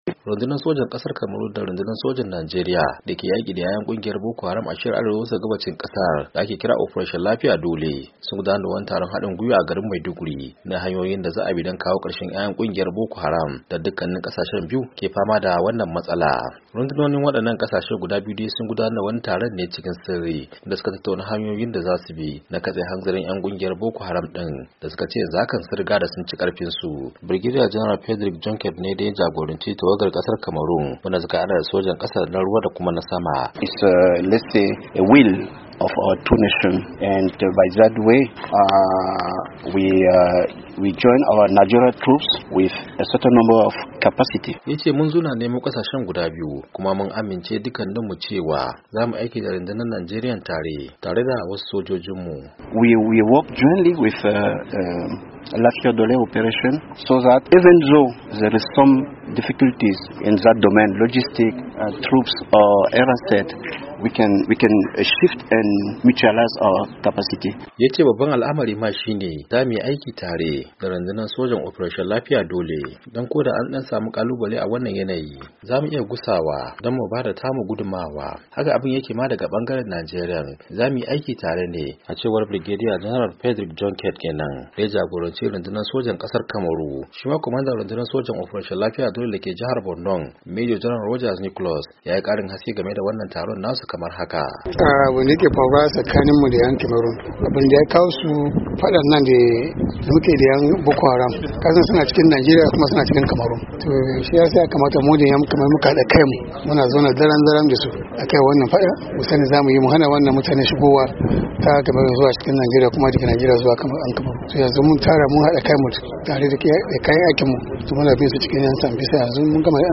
Ga cikakken bayani a wannan rahoto